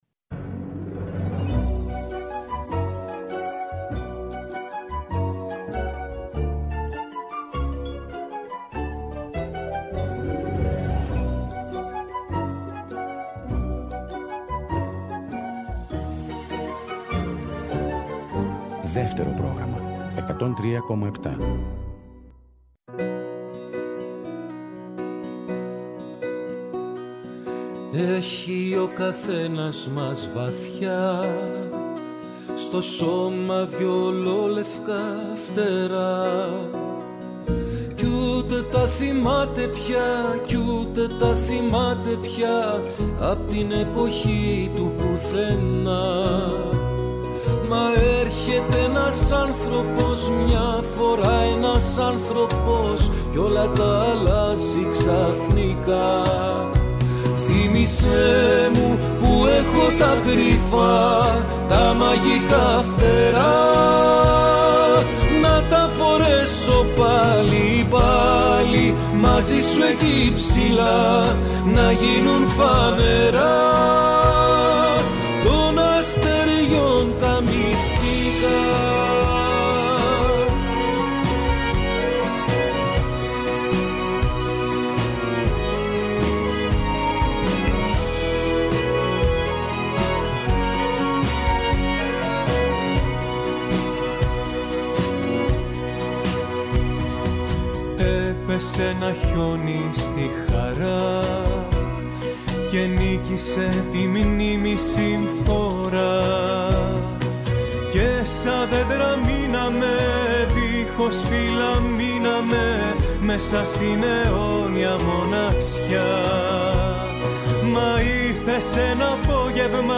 Τα τραγούδια της παρέας και πάντα το καινούργιο τραγούδι της ημέρας! Παλιά τραγούδια που κουβαλάνε μνήμες αλλά και νέα που πρόκειται να μας συντροφεύσουν.